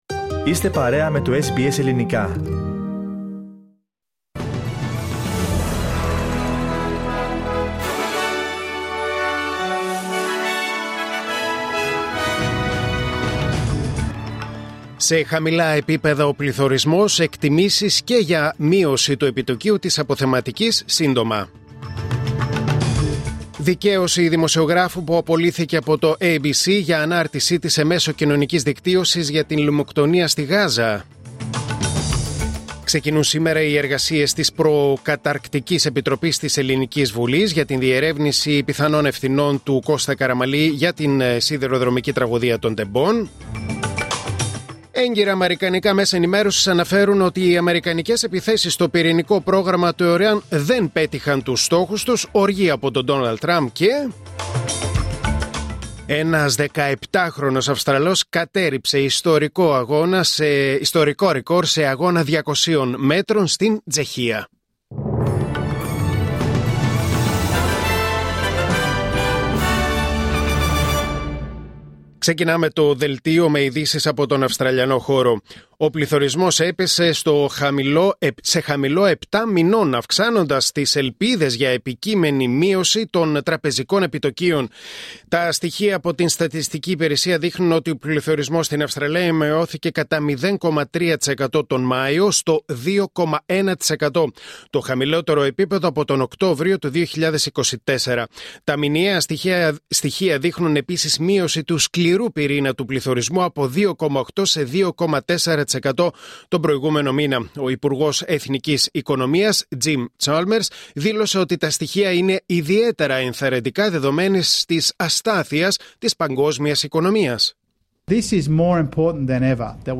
Δελτίο Ειδήσεων Τετάρτη 25 Ιουνίου 2025